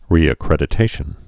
(rēə-krĕdĭ-tāshən)